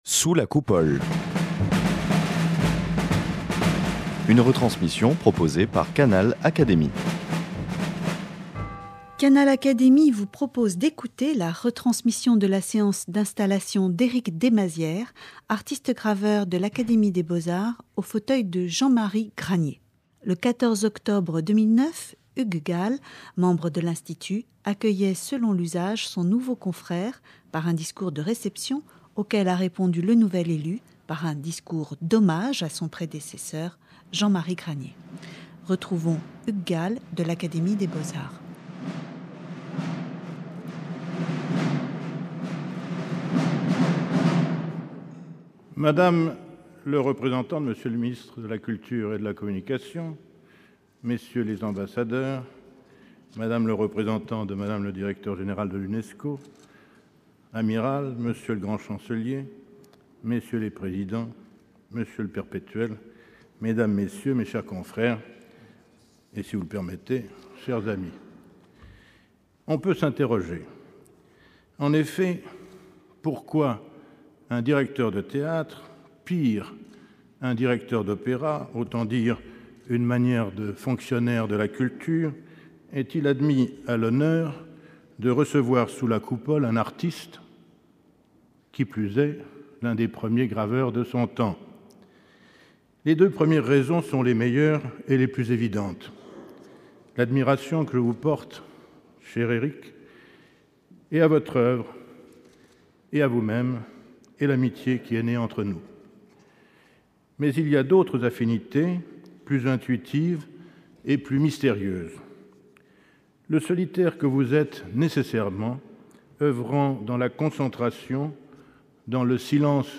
L’artiste graveur Erik Desmazières reçu sous la Coupole
Accueilli dans la section gravure, par Hugues Gall, membre de l’Académie, il a prononcé selon l’usage l’éloge de son prédécesseur, le graveur Jean-Marie Garnier. Lumière sur l’art de la gravure et sur une œuvre exceptionnelle.